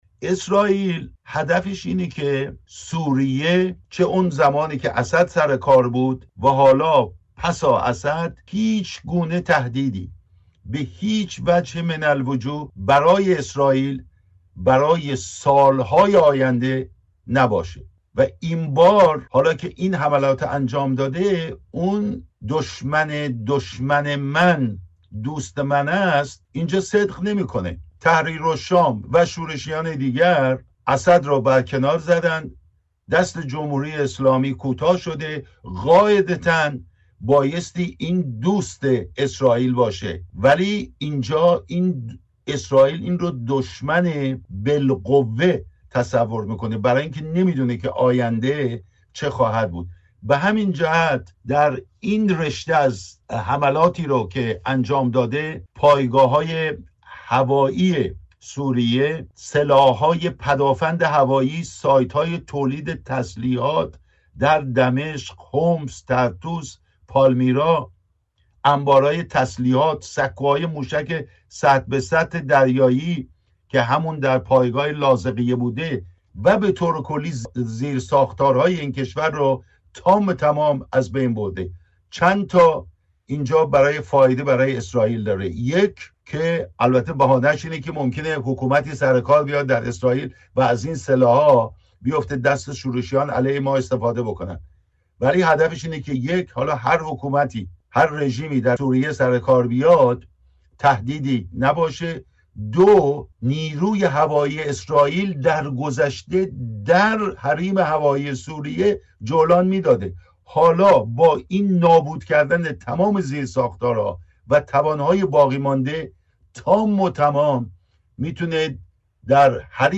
در پی سقوط حکومت بشار اسد در سوریه، اسرائیل مجموعه حملاتی را علیه زیرساخت‌های نظامی سوریه و تسلیحات راهبردی این کشور انجام داده است. اسرائیل همچنین اعلام کرده که نیروی دریایی سوریه را به طور کامل از بین برده است. در گفت‌وگو